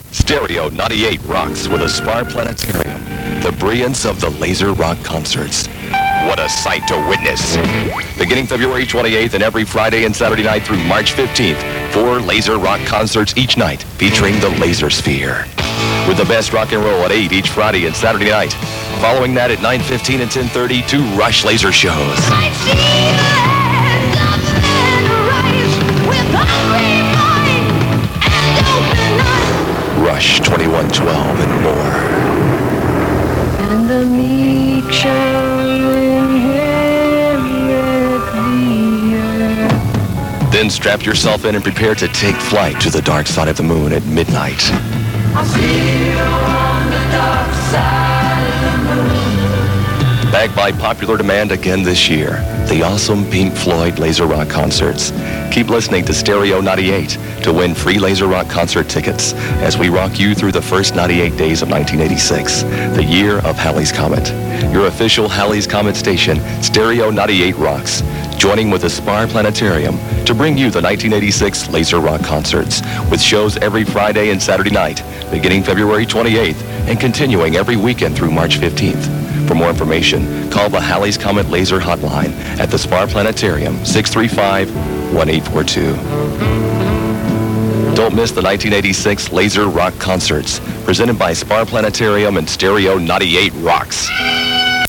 This show was produced for planetarium presentions in 1980.